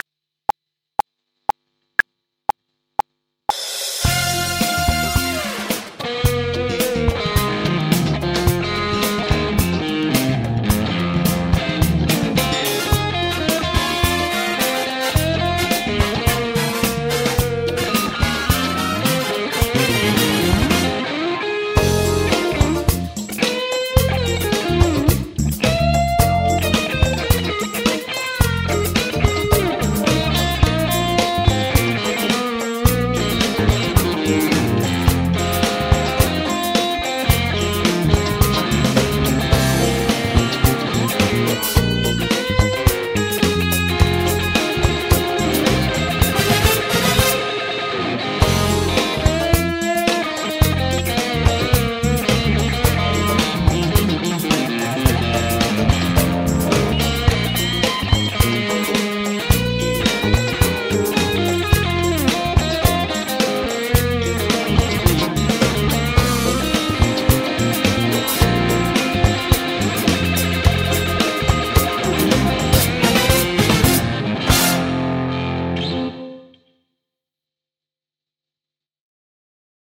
Selkeän voimakas soundi.
Rytmipuolella aika paljon vapausasteita vs napakasti polkeva tausta.
rjjon toimivaa laittoa, flow kestää alusta loppuun.
Sama juttu tässä eli keskivaiheilla hyvin toimivaa soittoa.